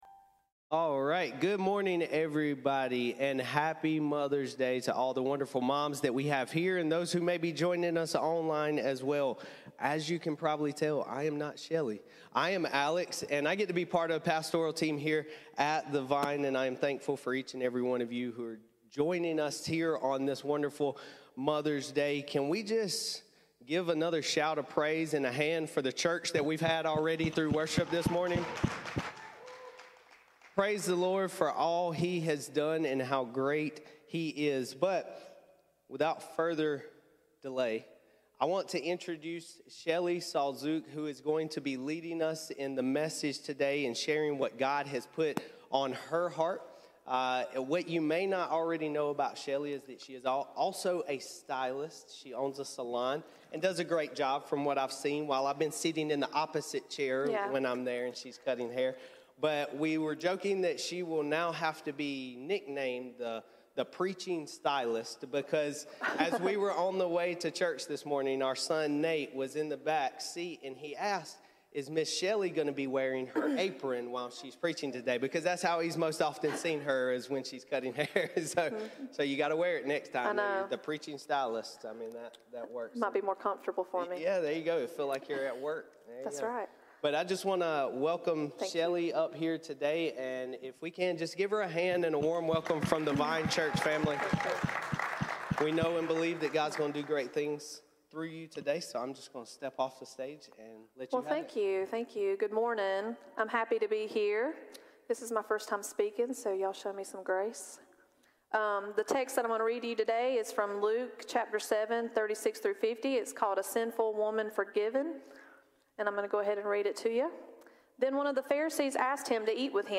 Mothers Day Guest Speaker